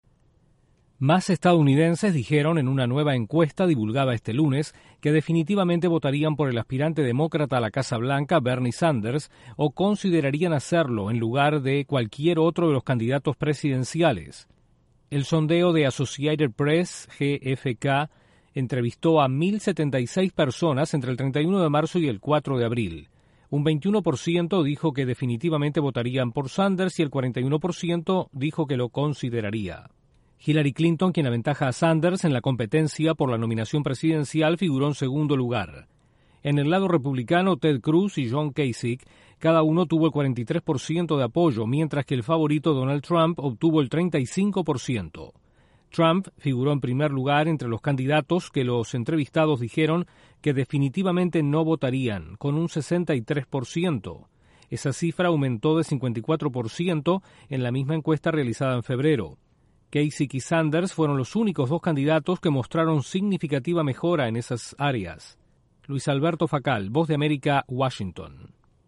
Una nueva encuesta en EE.UU. muestra a Bernie Sanders como el candidato preferido para la elección de noviembre. Desde la Voz de América en Washington informa